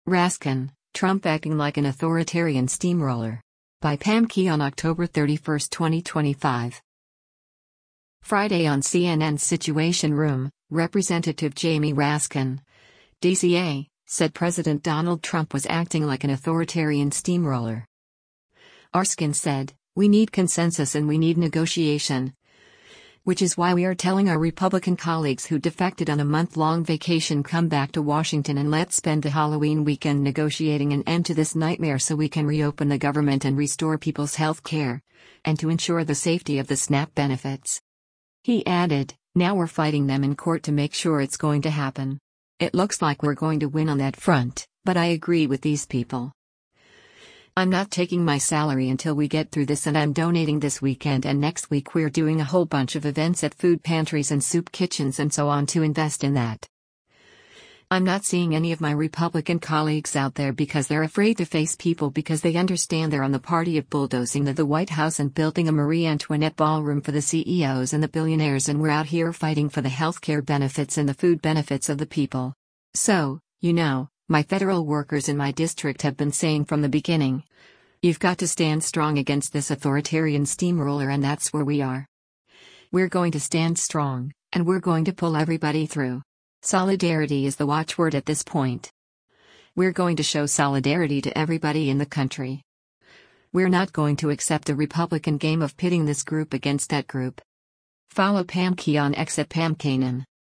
Friday on CNN’s “Situation Room,” Rep. Jamie Raskin (D-CA) said President Donald Trump was acting like an “authoritarian steamroller.”